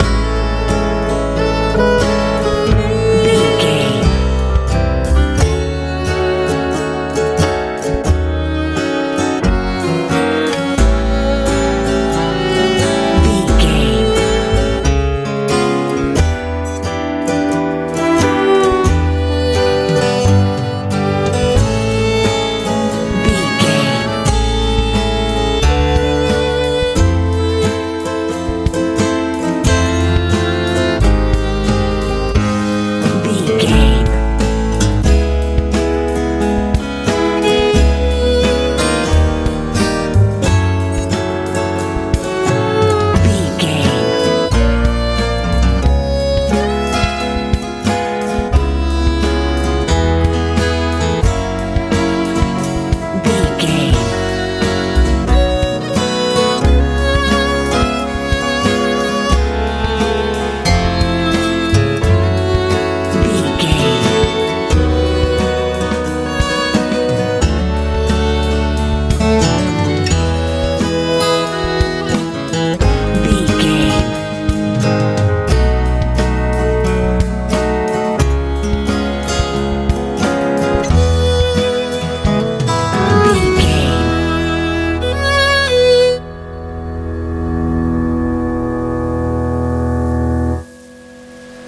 country ballad
Ionian/Major
violin
piano
acoustic guitar
bass guitar
drums
tranquil
soft
soothing
mellow
melancholy